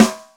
snare15.ogg